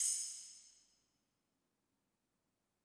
PERC - JILL.wav